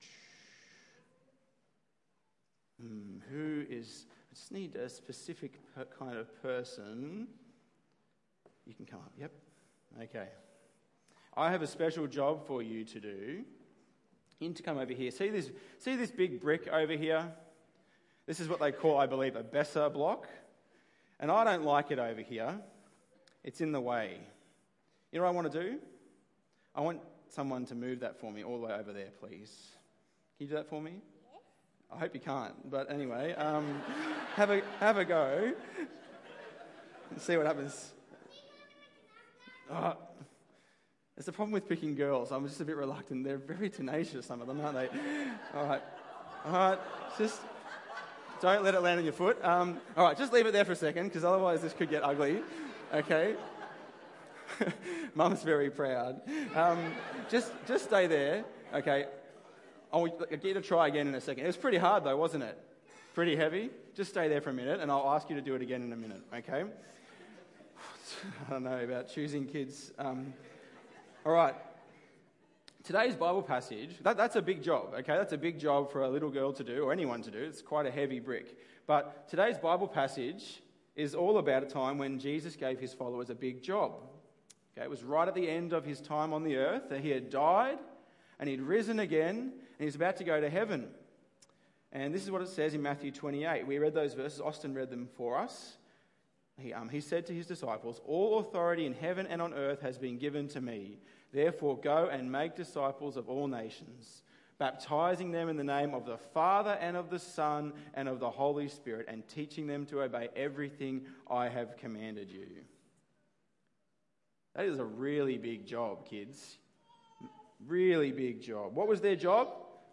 Kids holiday service: It matters who you’re with